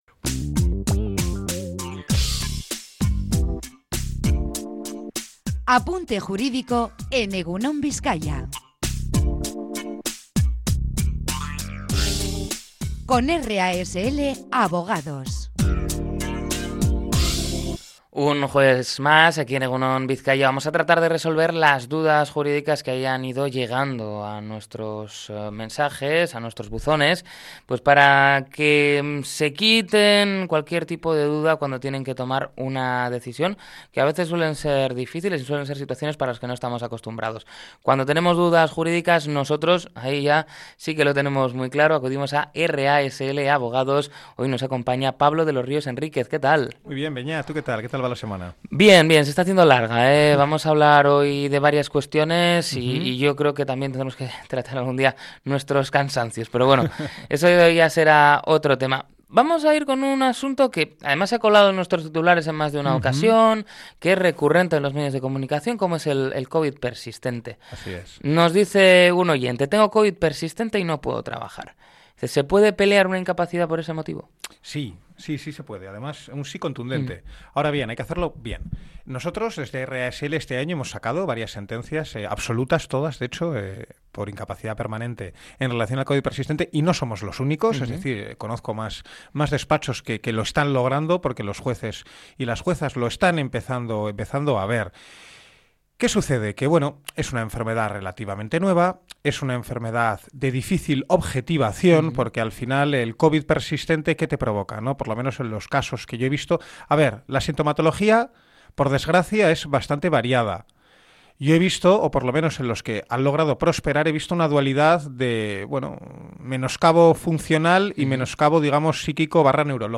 Podcast Sociedad